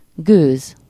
Ääntäminen
US
IPA : /stiːm/